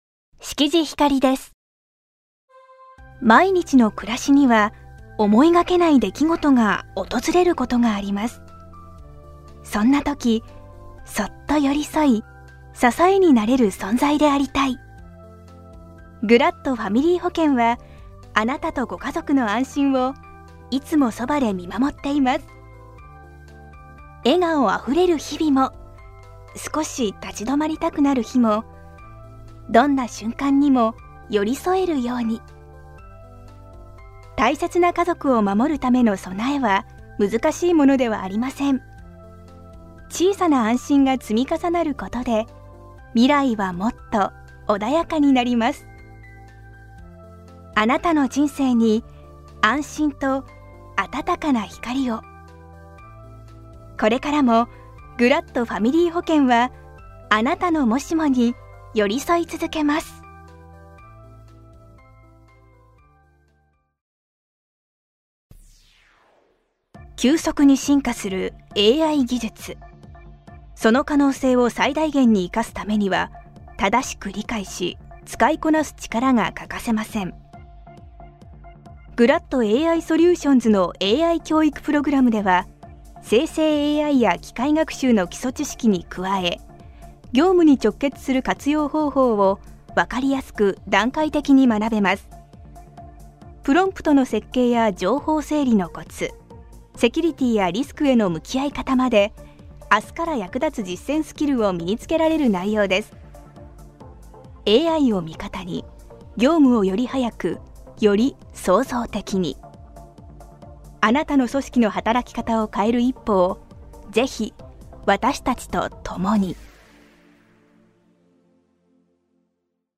ボイスサンプル
• 音域：高～中音
• 声の特徴：元気、明るい、キャラボイス